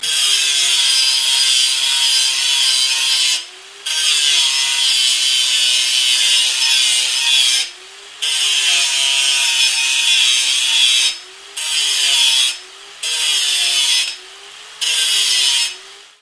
На этой странице собраны различные звуки болгарки: от коротких резких скрежетов до продолжительной работы инструмента.
Осторожно: громко!
Звук стремительного вращения болгарки - альтернативный вариант